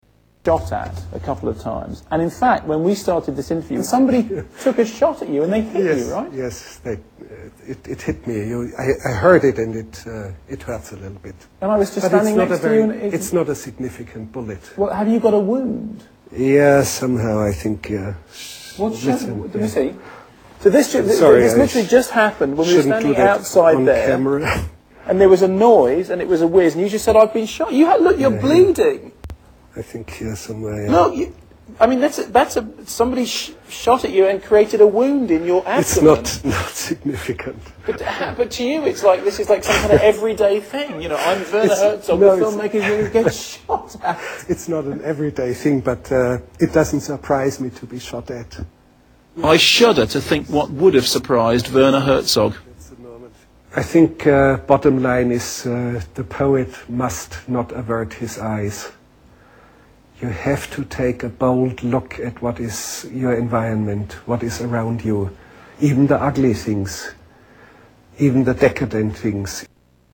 Gets Shot During Interview